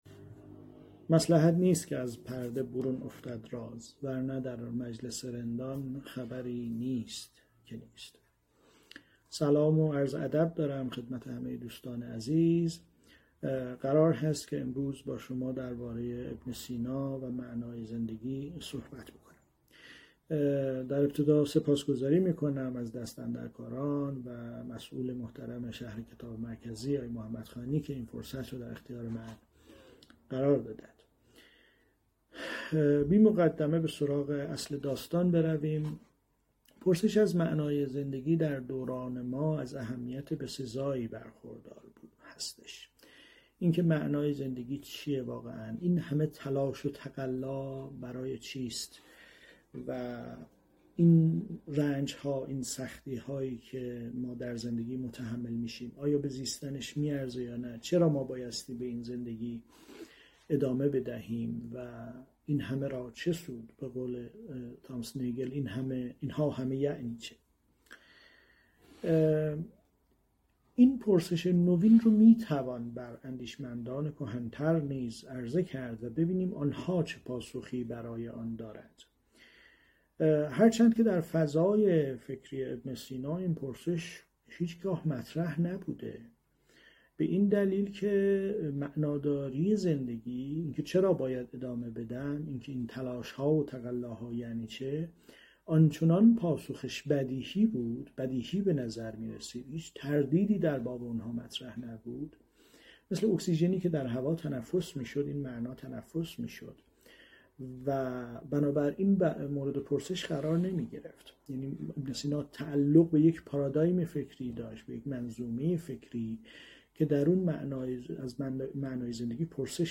این درس‌گفتار به صورت مجازی از اینستاگرام شهر کتاب پخش شد.